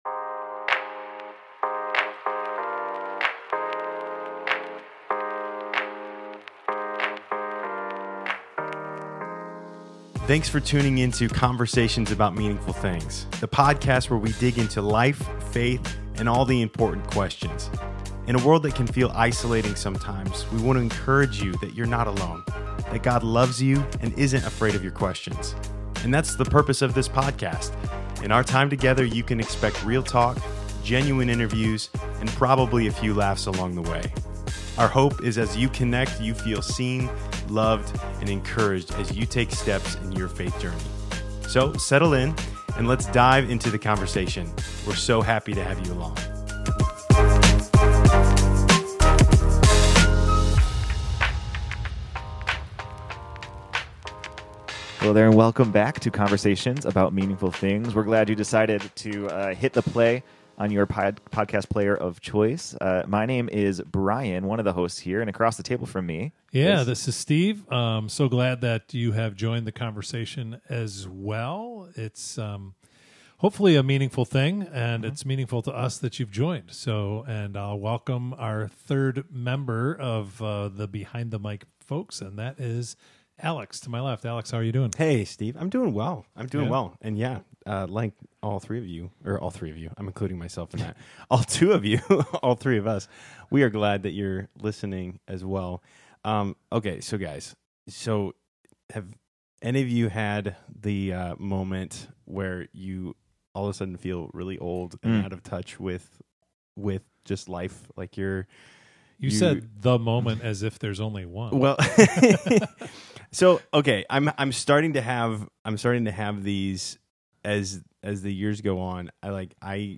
Episode 26: Can't Relate - A Conversation About Crossing Generational Lines